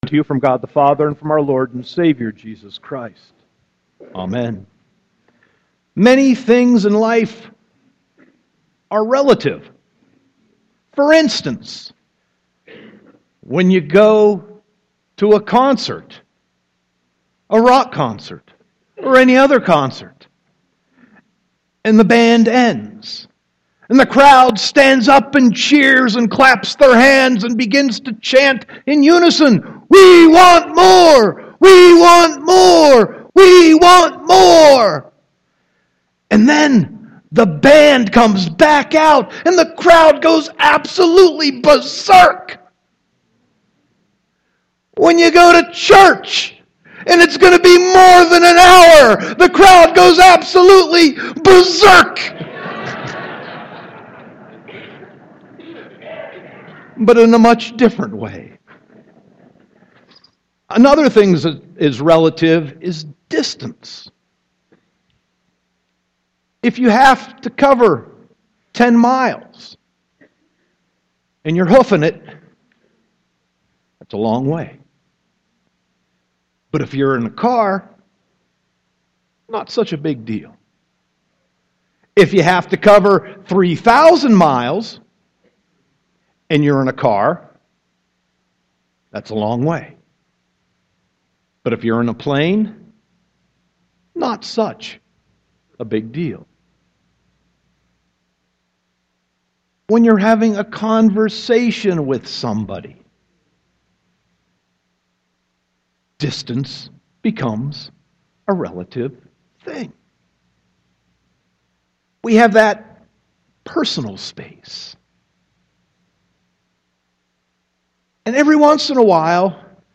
Sermon 3.20.2016
March 20, 2016 Palm Sunday (Readings)